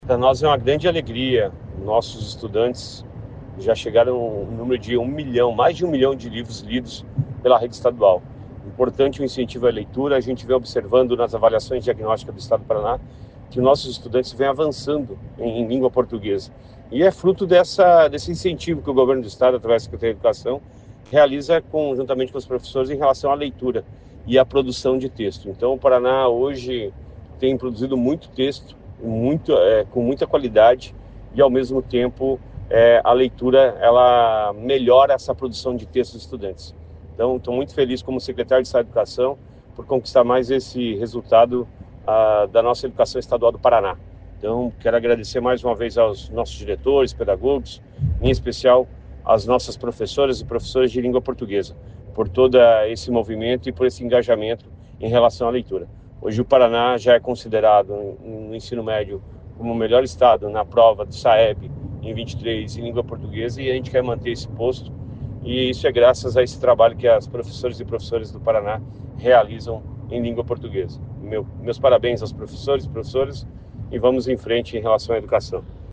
Sonora do secretário Estadual da Educação, Roni Miranda, sobre a marca de um milhão de livros lidos na plataforma Leia Paraná